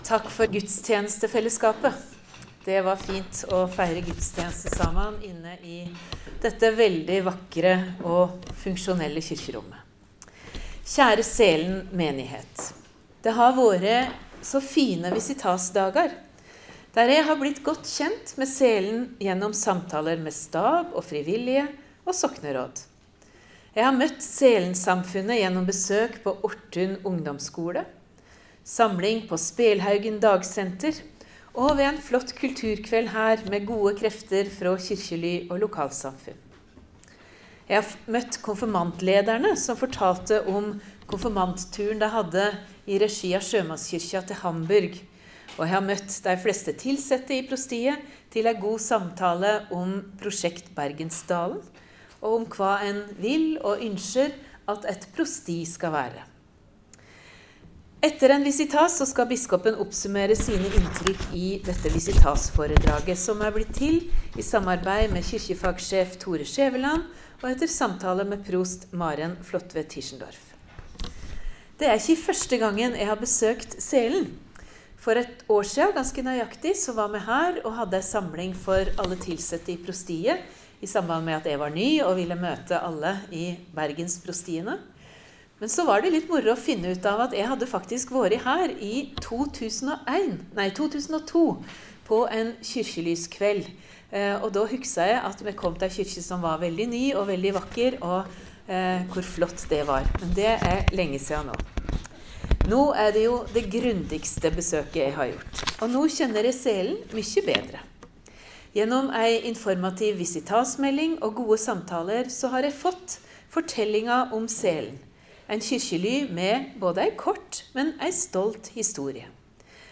Dokument Visitasføredrag Sælen 17. november 2024